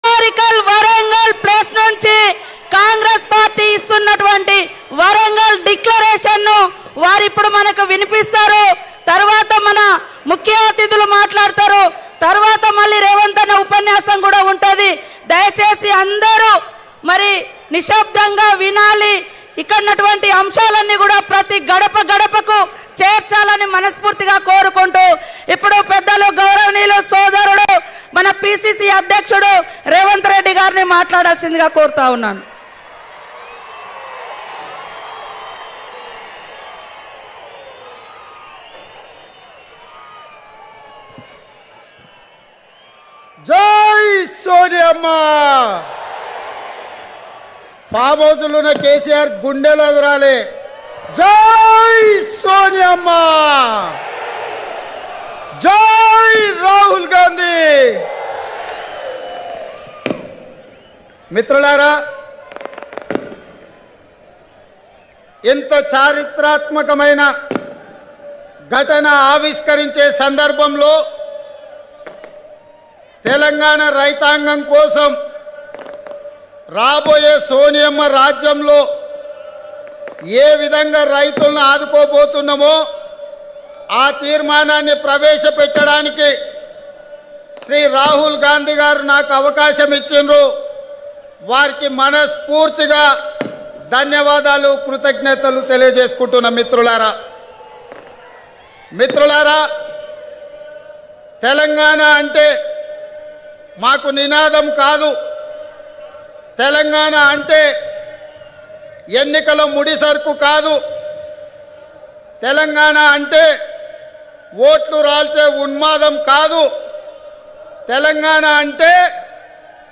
Revanth Reddy Speech at Rythu Sangharshana Sabha Live  Telangna Congress Party Live   Ntv.wav